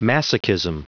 Prononciation du mot masochism en anglais (fichier audio)
Prononciation du mot : masochism